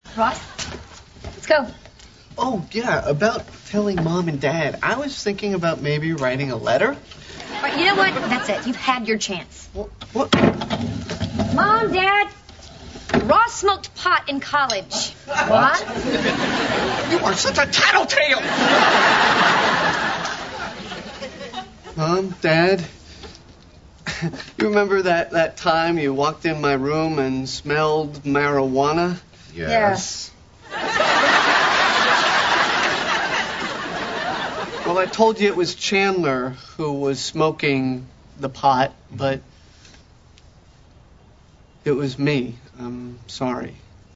Tattletale是指那些喜欢告状的小孩子，一般来说只有孩子们才会用这个词，所以当罗斯这么说莫妮卡时就显得他很孩子气，因而引来了一片笑声。